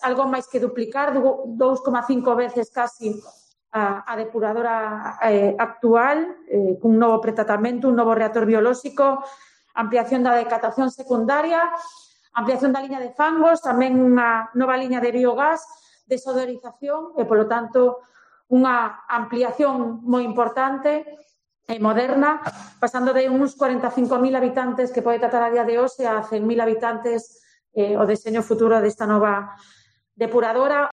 Declaraciones de Ethel Vázquez, conselleira de Infraestructuras